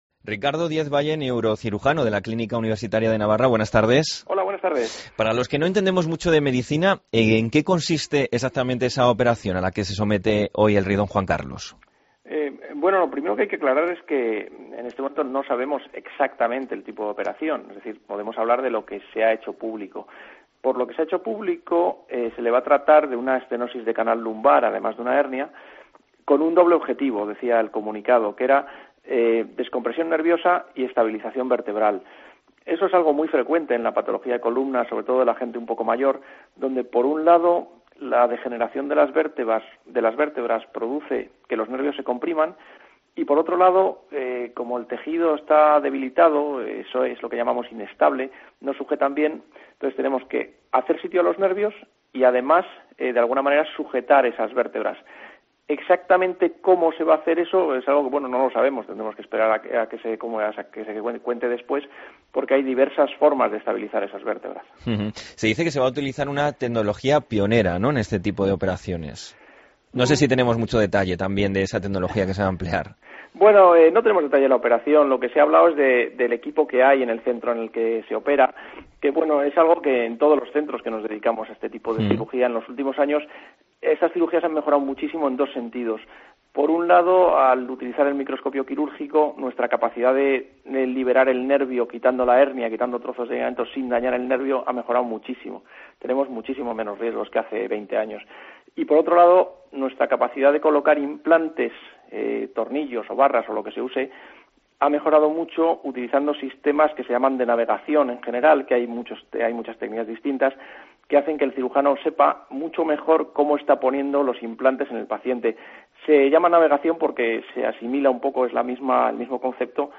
Entrevistamos a neurocirujano del Hospital Universitario de Navarra